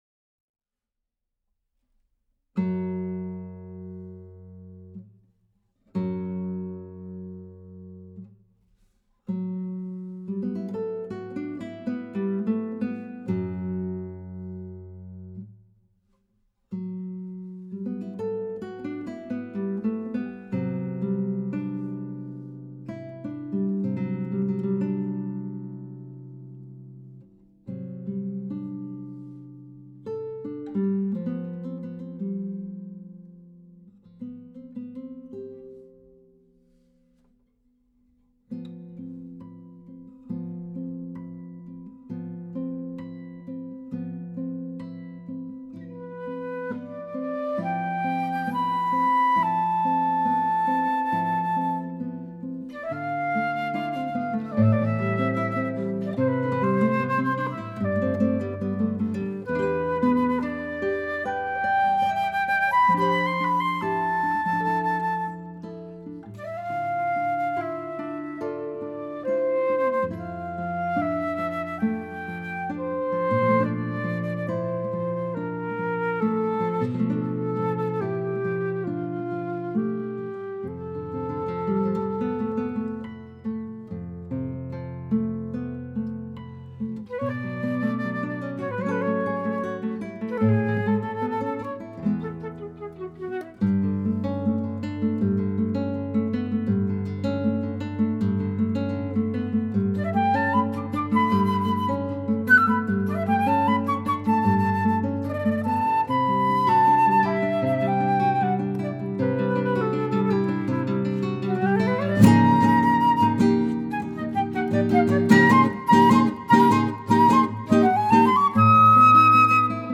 Me alegra mucho anunciaros que ya está disponible, para su descarga gratuita, la partitura de mi obra para flauta y guitarra titulada «Y a lo lejos tu sonrisa».